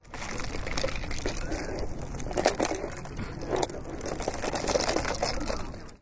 描述：这是一段有人在摇晃他们的冰杯和健怡百事可乐的录音。 我在Coho外面用Roland Edirol录制的。